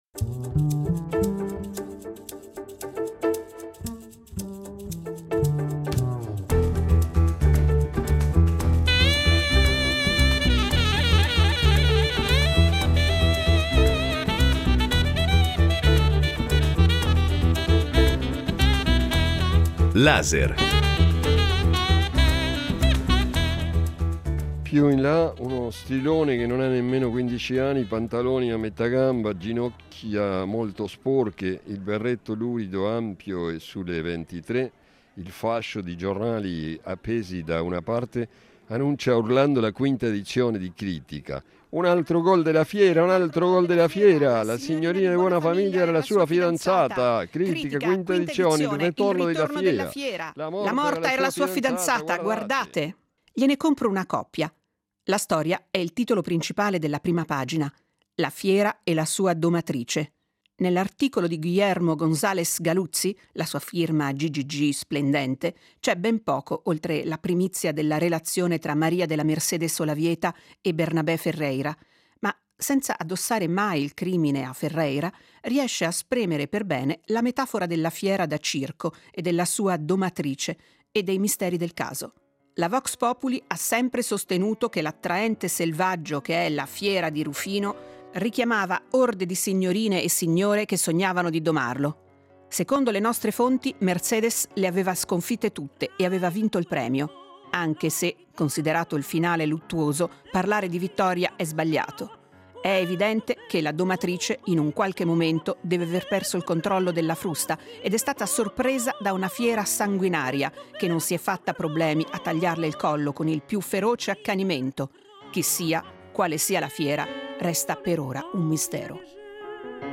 Laser ha incontrato Caparros a Mantova e con lui ha dialogato di letteratura e dell’America latina.